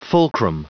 Prononciation du mot fulcrum en anglais (fichier audio)
Prononciation du mot : fulcrum